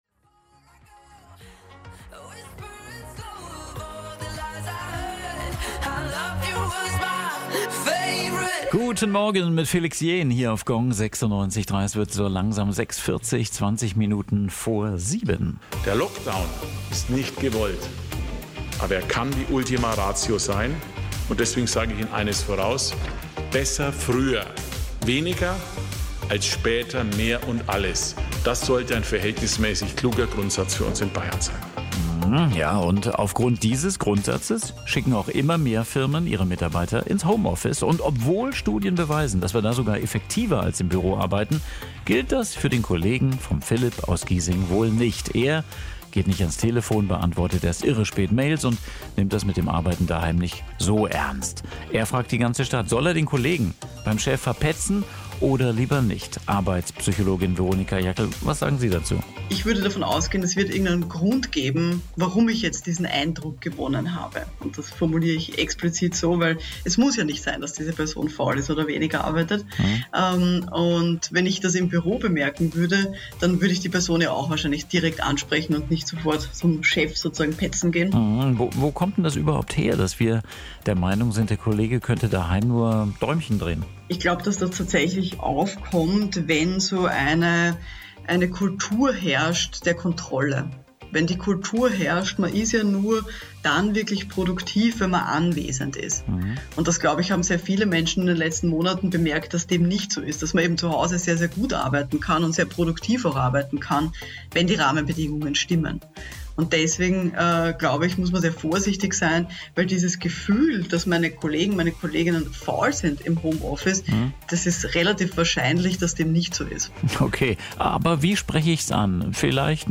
Radiobeitrag vom 22. Oktober 2020